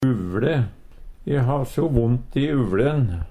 uvLe - Numedalsmål (en-US)
uvLe handledd Eintal ubunde Eintal bunde Fleirtal ubunde Fleirtal bunde uvLe uvLen uvLa uvLan Eksempel på bruk E har so vondt i uvLen. Høyr på uttala Ordklasse: Substantiv hankjønn Kategori: Kropp, helse, slekt (mennesket) Attende til søk